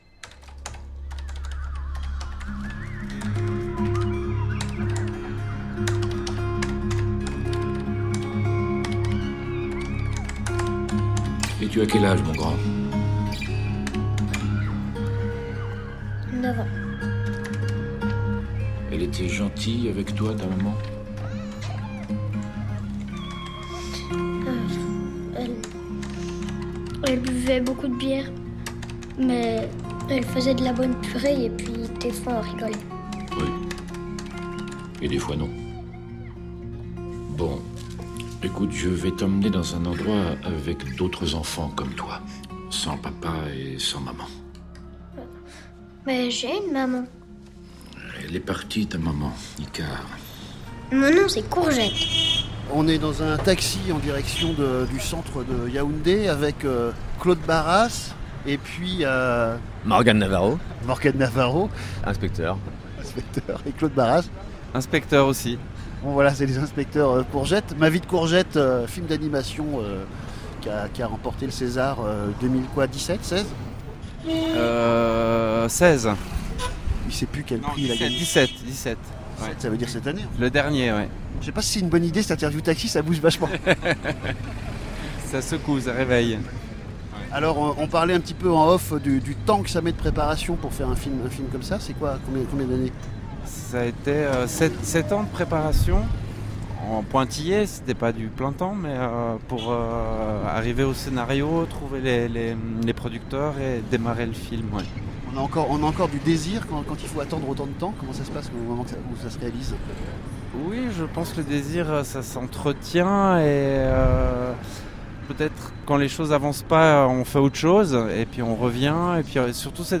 Rencontre en taxi à Yaoundé (Cameroun) lors des trophées du film francophone